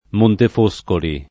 vai all'elenco alfabetico delle voci ingrandisci il carattere 100% rimpicciolisci il carattere stampa invia tramite posta elettronica codividi su Facebook Montefoscoli [ montef 0S koli o montef 1S koli ] top.
— in val d’Era — con -o- aperto la pn. locale — cfr. fosco